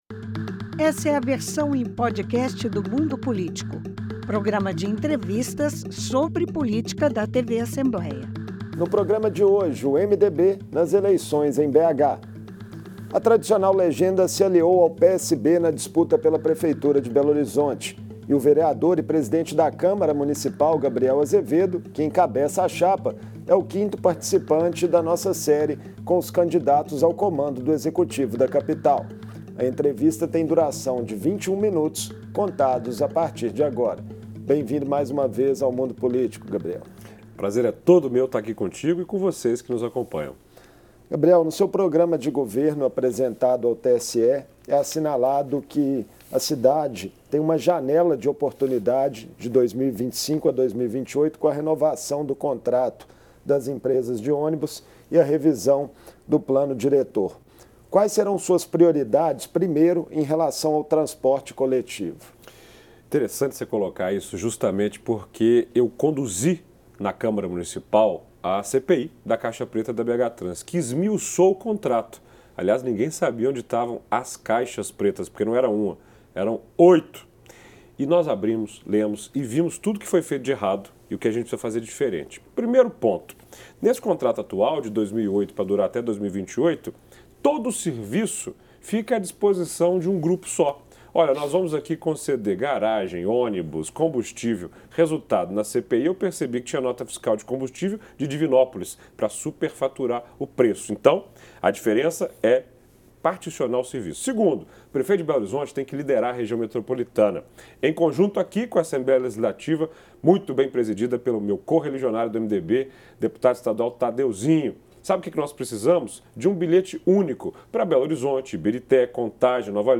O Mundo Político faz a quinta entrevista da série com o candidato Gabriel Azevedo que é vereador e presidente da Câmara da capital. Gabriel tem entre os destaques do seu programa de governo a mobilidade urbana.